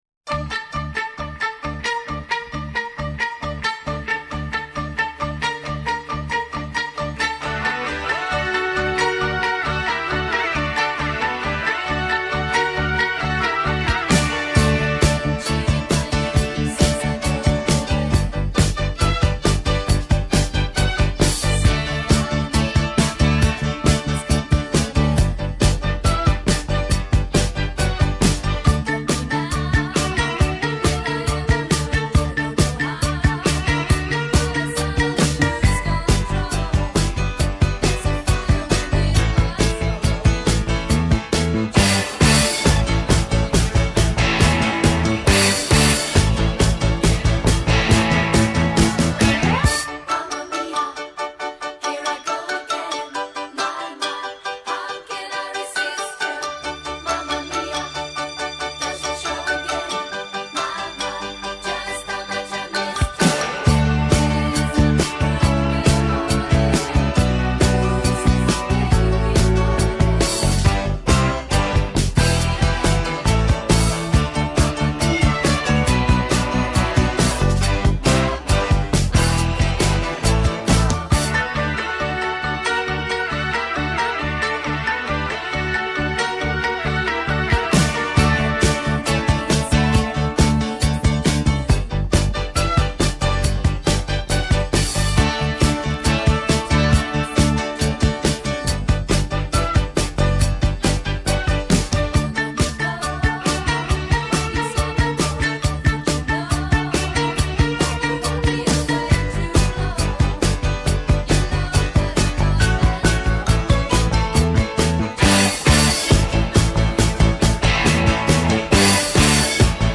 (Karaoke Version)